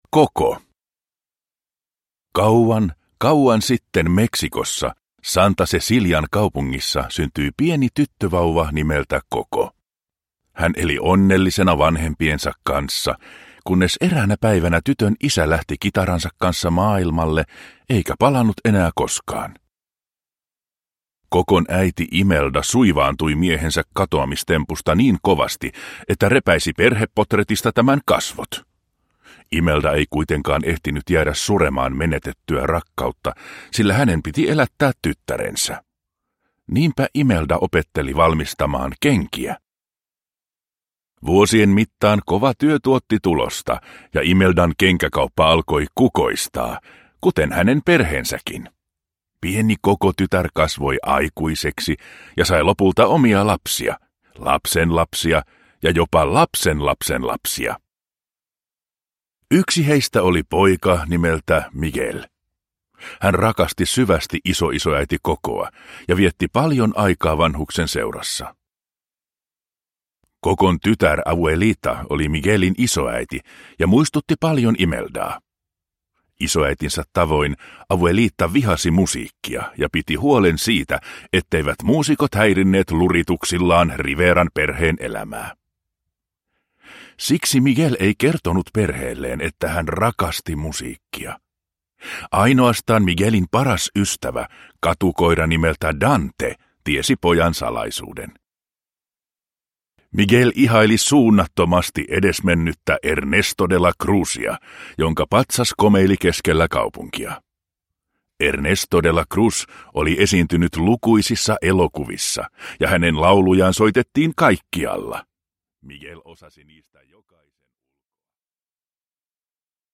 Coco – Ljudbok – Laddas ner